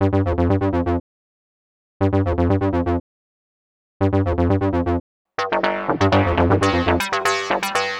Session 08 - Retro Lead 01.wav